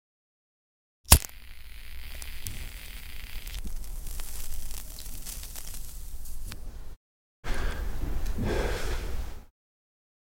Звук зажигания сигареты транжиром